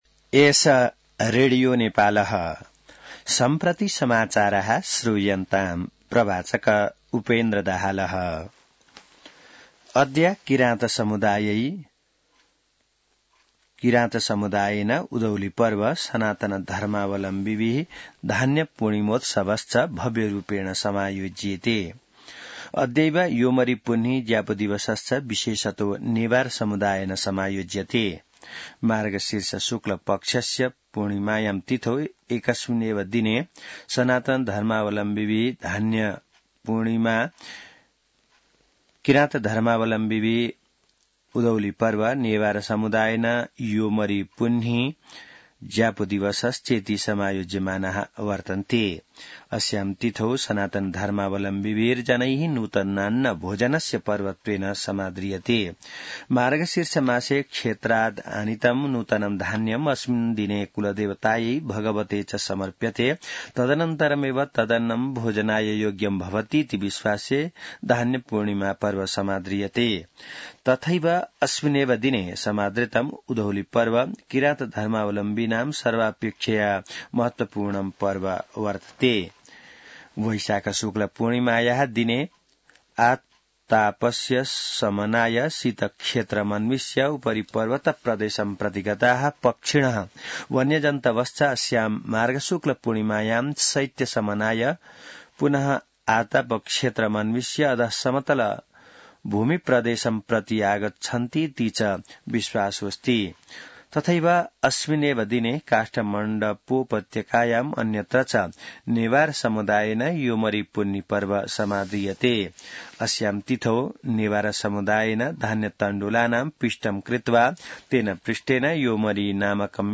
संस्कृत समाचार : १ पुष , २०८१